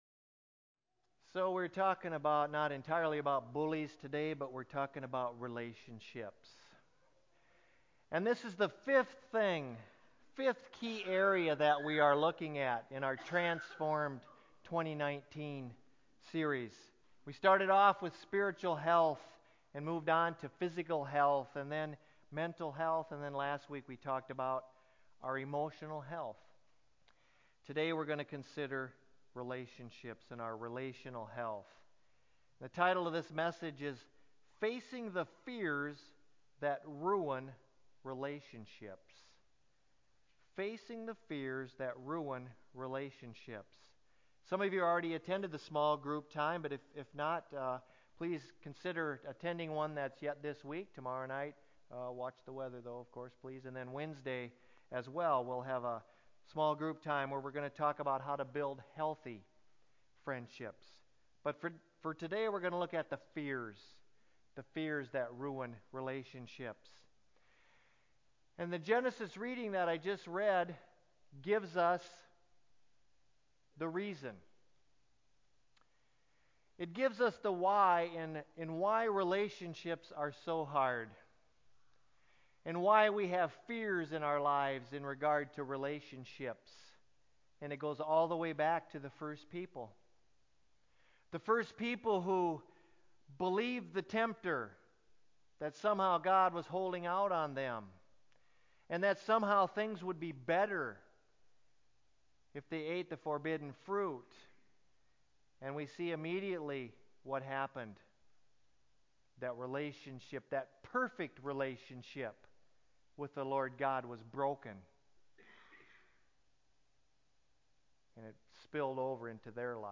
Church_Sermon_2.10-CD.mp3